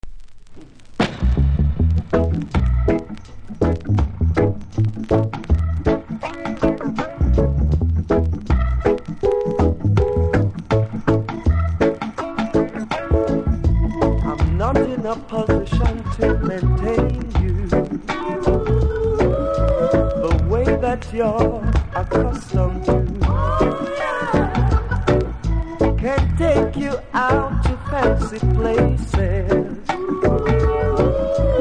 多少キズありますが音は良好です。